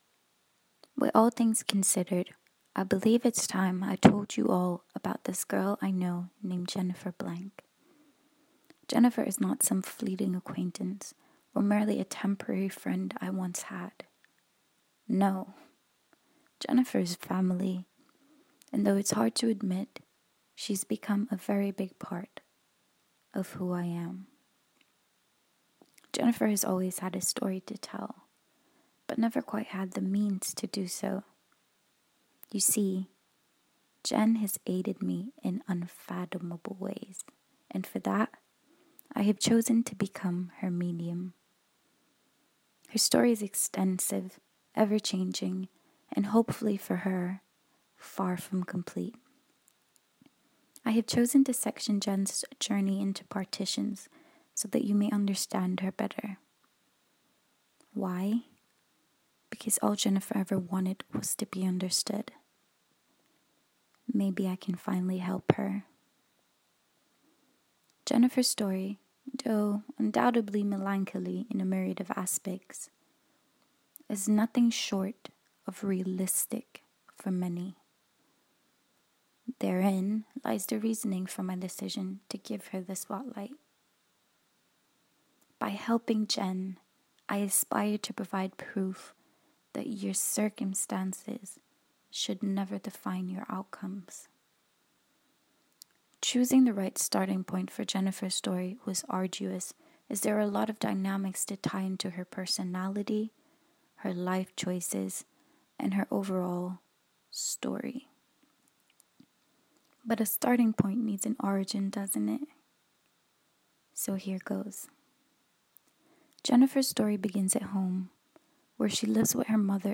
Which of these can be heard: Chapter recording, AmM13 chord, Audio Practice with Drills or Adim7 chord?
Chapter recording